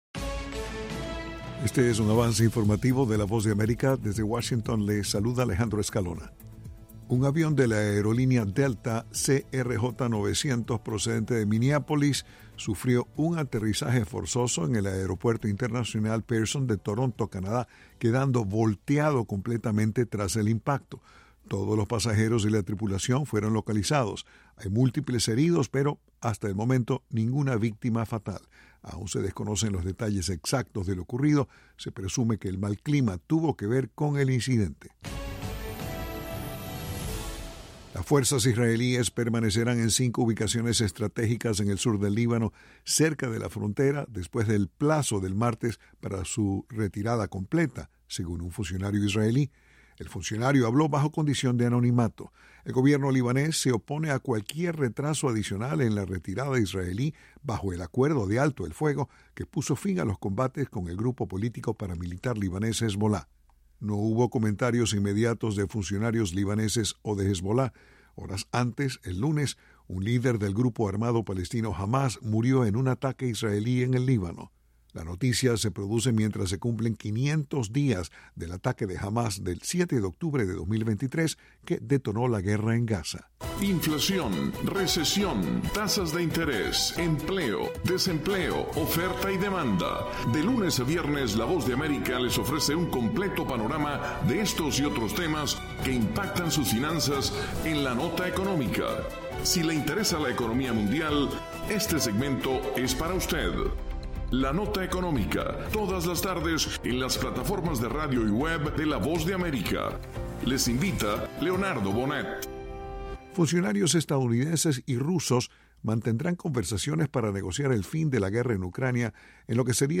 El siguiente es un avance informativo de la Voz de América.